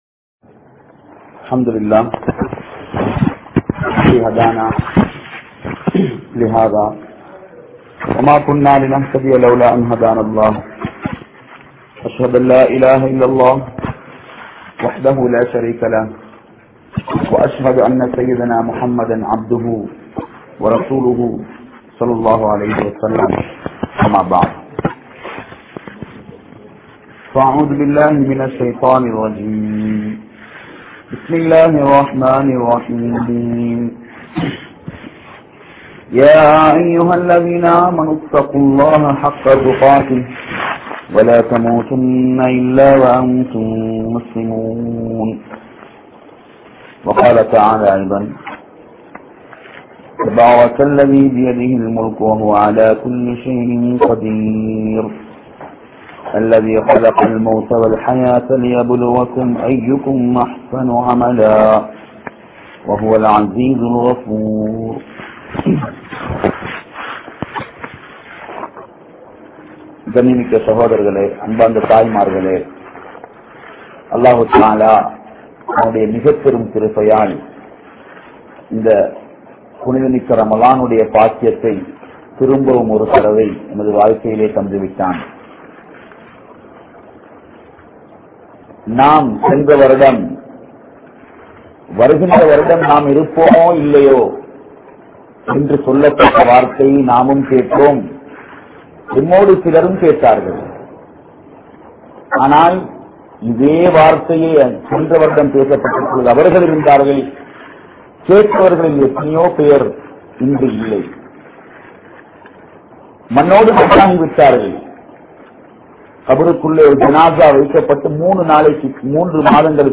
Pengalai Paathu Haarungal | Audio Bayans | All Ceylon Muslim Youth Community | Addalaichenai